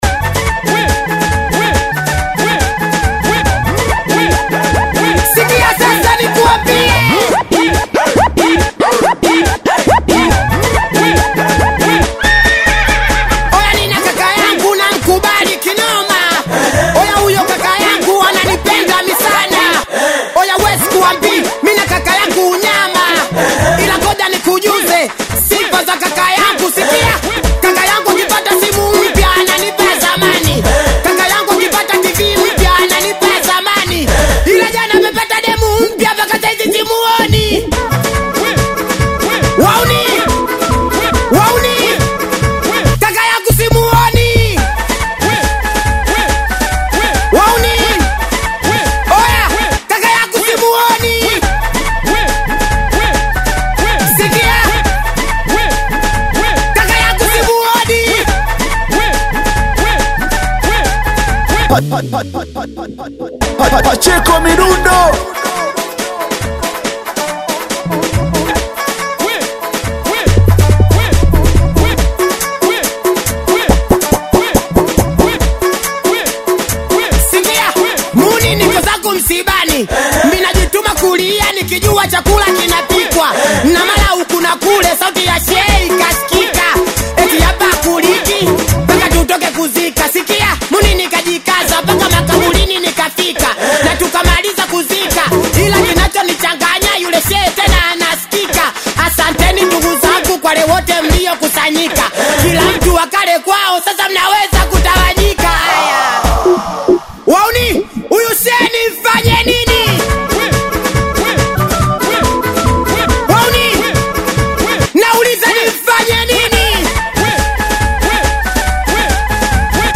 Singeli music track
Tanzanian Singeli artist, singer, and songwriter